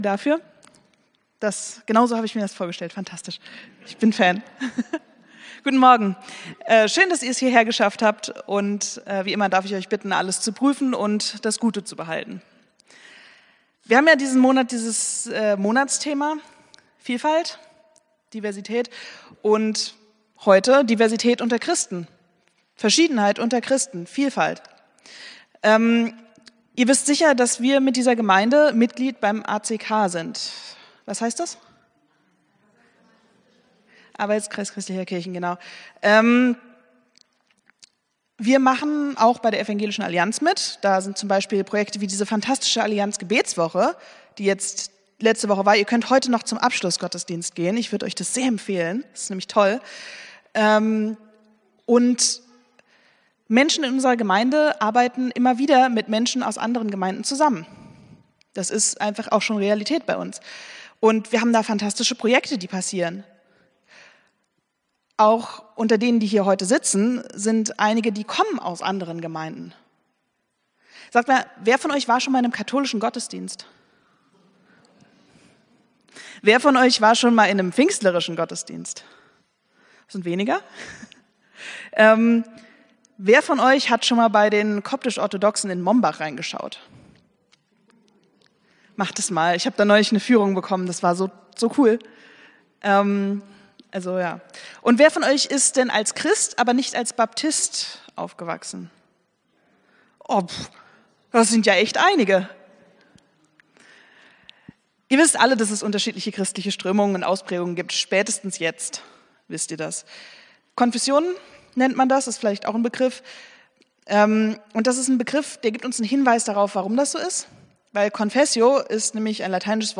Predigt vom 19.01.2025